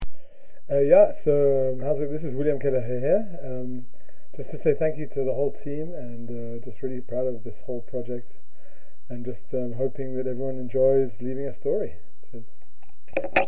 Cabine de témoignages
Témoignage du 30 juin 2025 à 18h39